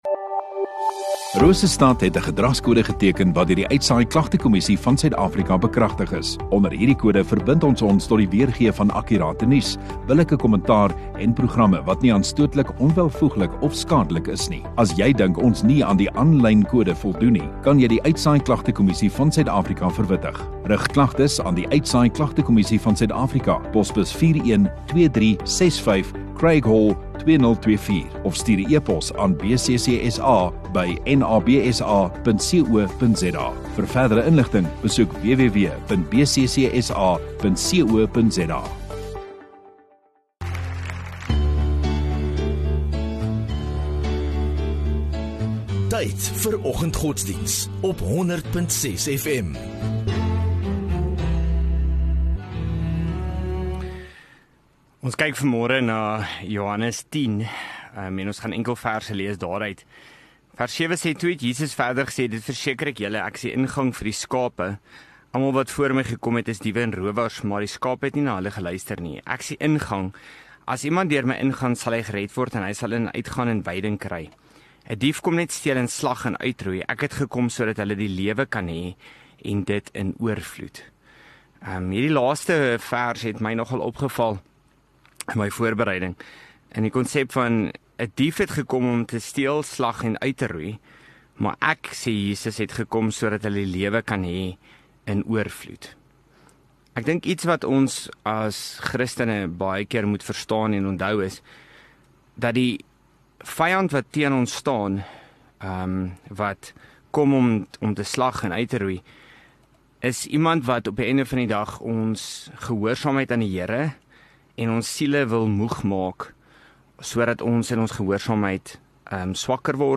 9 Dec Dinsdag Oggenddiens